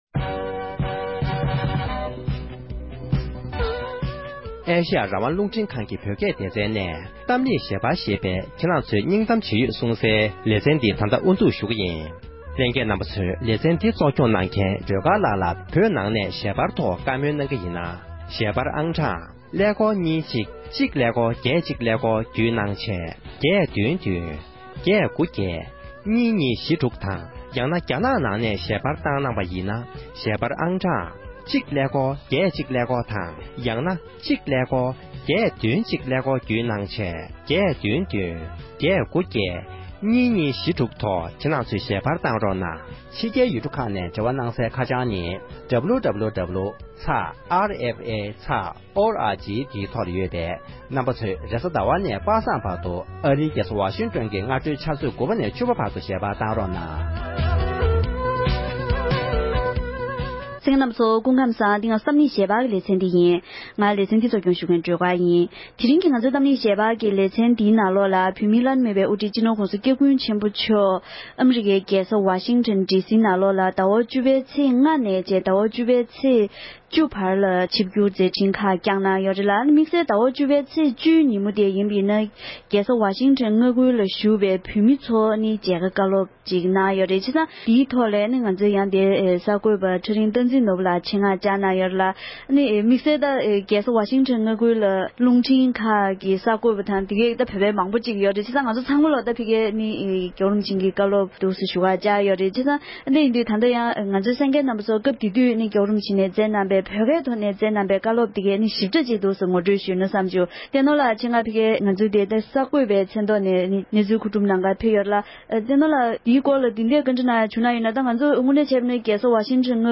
༄༅༎དེ་རིང་གི་གཏམ་གླེང་ཞལ་པར་གྱི་ལེ་ཚན་ནང་བོད་མིའི་བླ་ན་མེད་པའི་དབུ་ཁྲིད་སྤྱི་ནོར་༸གོང་ས་༸སྐྱབས་མགོན་ཆེན་པོ་མཆོག་ནས་ཨ་རིའི་རྒྱལ་ས་ཝ་ཤིང་ཀྲོན་བོད་མི་རྣམས་ལ་མཇལ་ཁ་དང་བཀའ་སློབ་བསྩལ་ཡོད་པའི་སྐོར་བགྲོ་གླེང་ཞུས་པ་ཞིག་གསན་རོགས༎